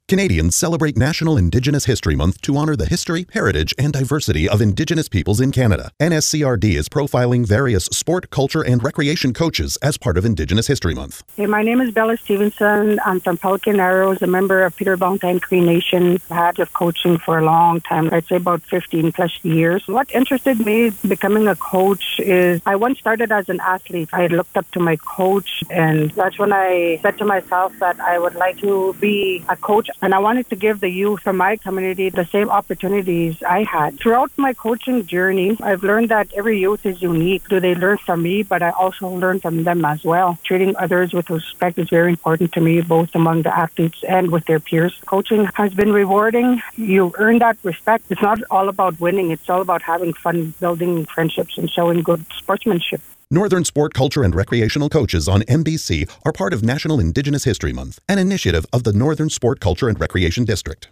Several messages from Indigenous leaders have been produced to celebrate National Indigenous History Month.
A partnership with MBC Radio.